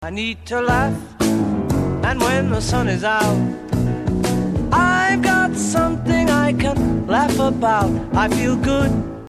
fait descendre chromatiquement toutes les notes
descente chromatique